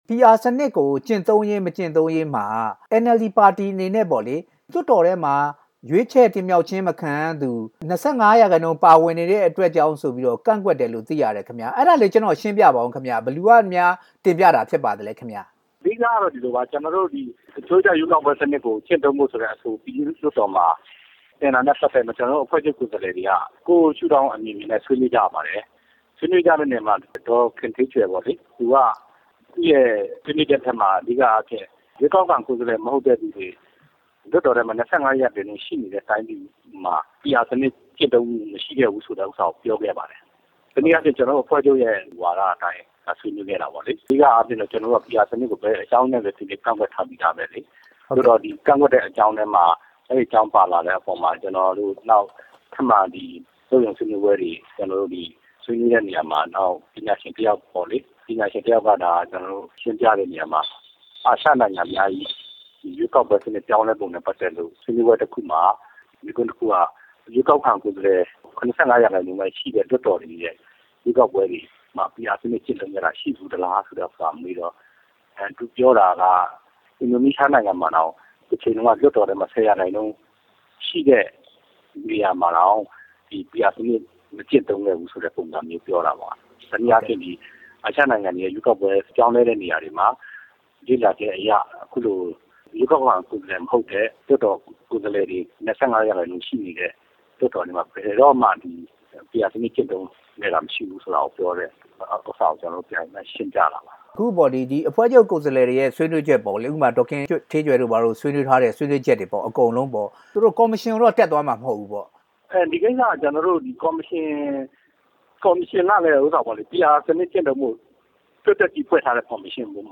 PR စနစ်ကို ကန့်ကွက်ရတာနဲ့ ပတ်သက်ပြီး မေးမြန်းချက်